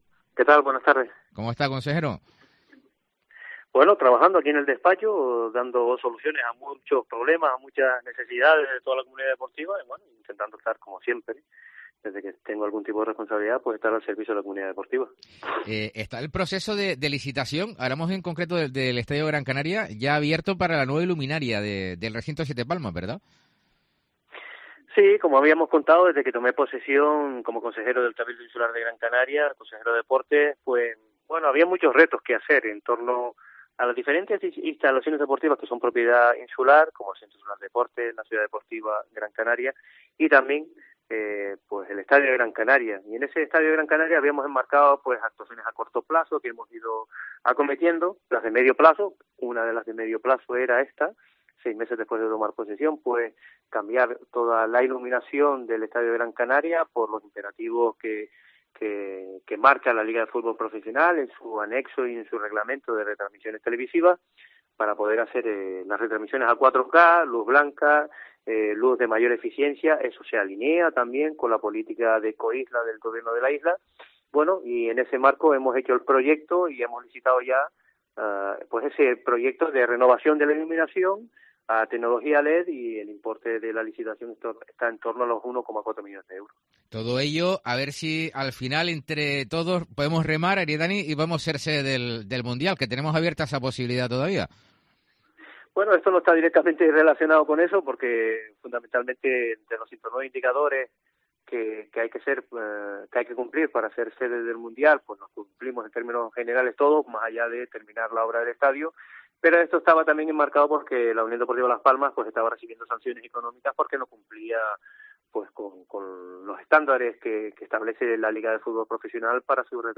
Entrevista a Aridany Romero, consejero de Deportes del Cabildo de Gran Canaria